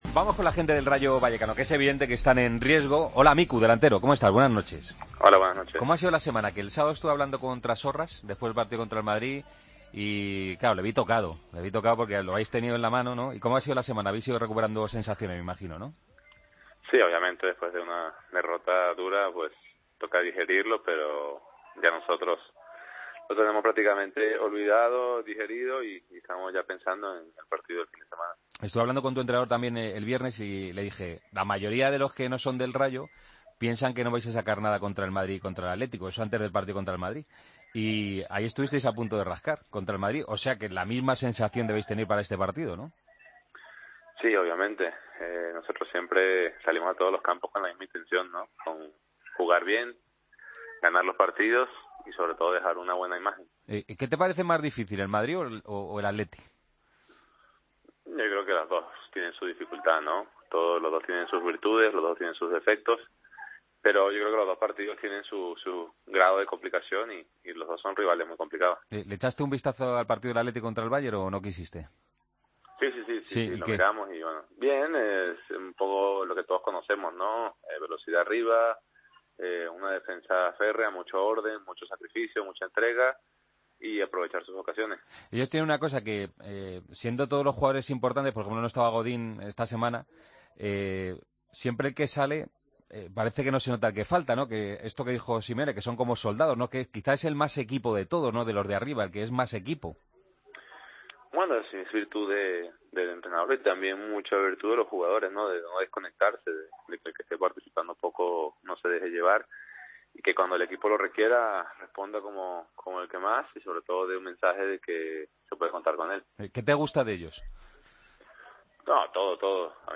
El jugador del Rayo habla en la previa frente al Atlético: "Después de una derrota dura, nosotros lo tenemos casi olvidado. Tanto el Madrid y Atlético son díficiles, cada equipo tiene sus virtudes. A mi lo que más me gusta del Atlético, es el ambiente del campo, parecido al nuestro, pero más pequeño. Dependemos de nosotros para seguir en 1ª. Muchos otros quisieran estar en nuestra situación”.